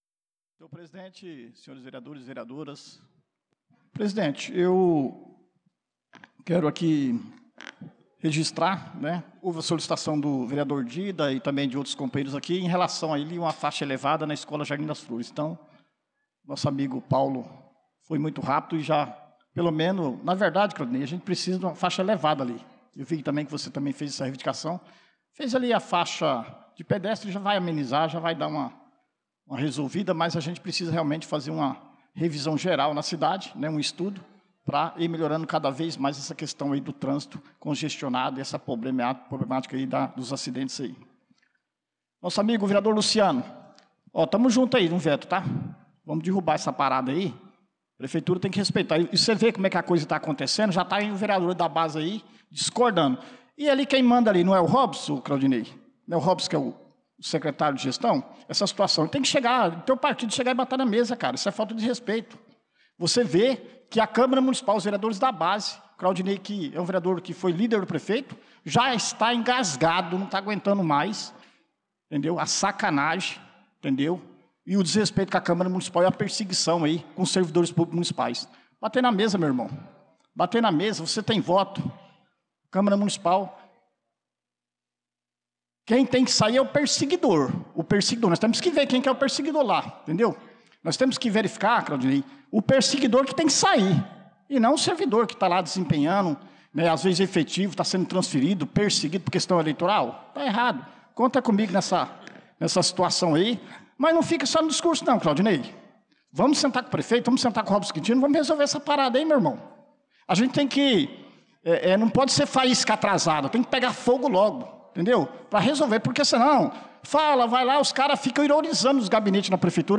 Pronunciamento do vereador Dida Pires na Sessão Ordinária do dia 01/04/2025